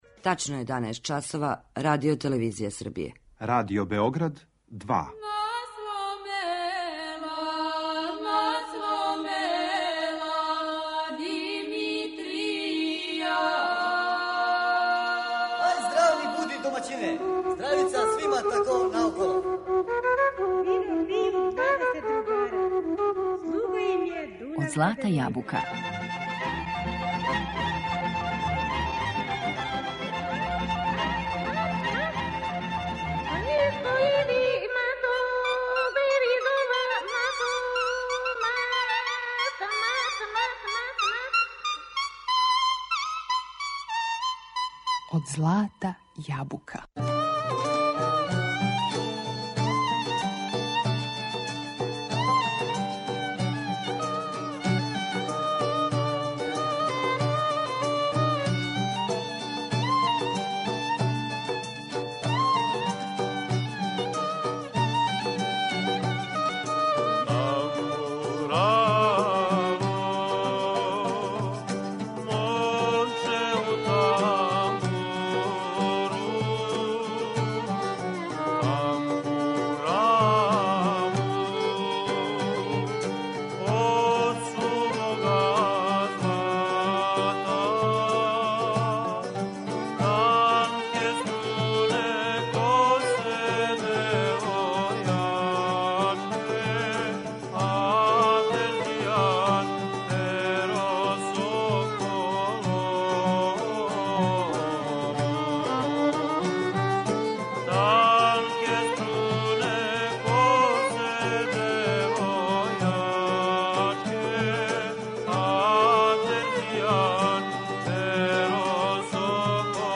Прескочићемо уобичајени увод о пореклу, развоју инструмента и најистакнутијим композиторима и вођама тамбурашких састава, и препустићемо се слушању звука овог и сродних му инструмената.